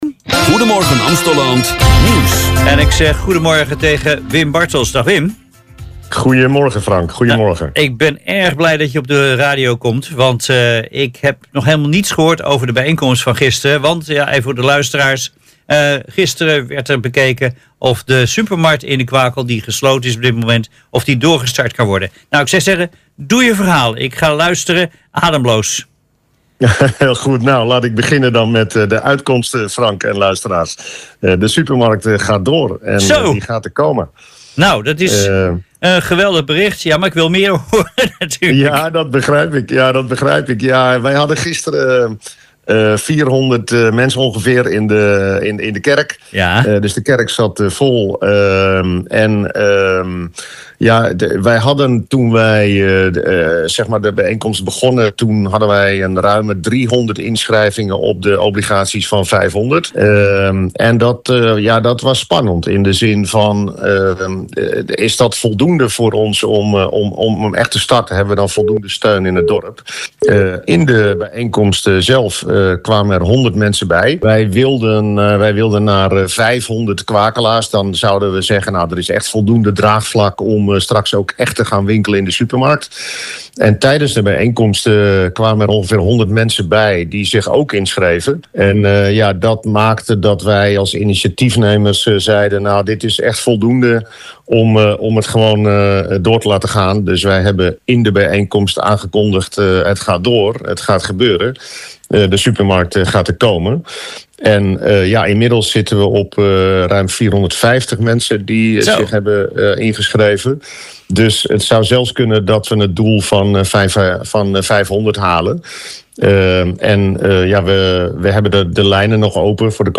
in de uitzending op RickFM: